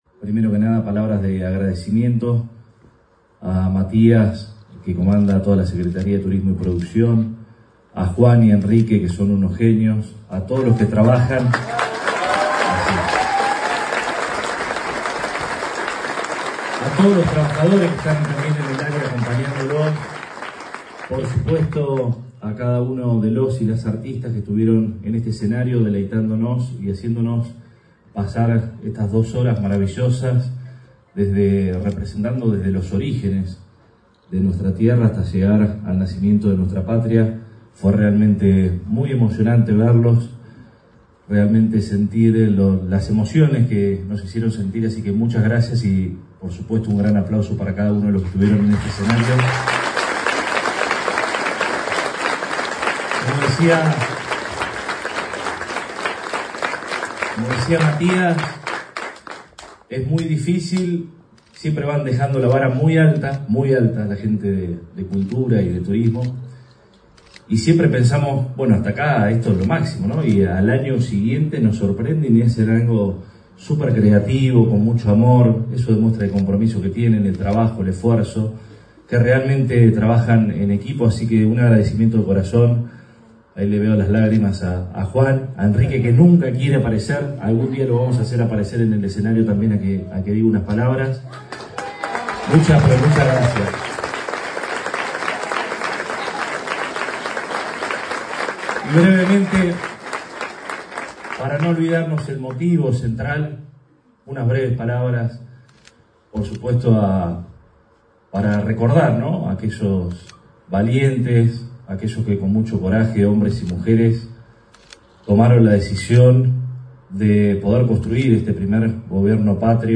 Luego de una emocionante gala artística en el Teatro Municipal, el intendente Arturo Rojas brindó un cálido mensaje a la comunidad, resaltando los valores de la Revolución de Mayo e instando a construir patria todos los días con “solidaridad y empatía”.
Con la sala “Milagros De La Vega” del Teatro Municipal “Luis Sandrini” completamente colmada, y en el marco de una gala artística que emocionó a todos los presentes, el intendente Arturo Rojas encabezó en la víspera del domingo 25 de mayo la ceremonia por el 215° aniversario de la Revolución de Mayo.